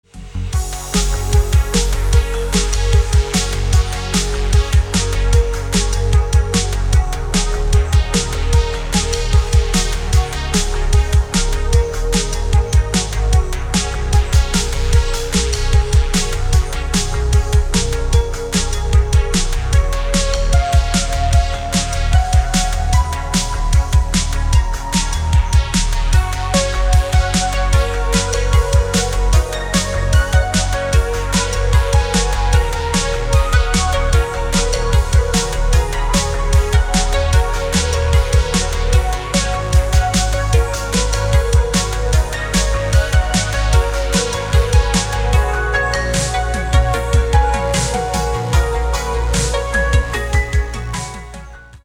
красивые
dance
Electronic
электронная музыка
спокойные
без слов
Downtempo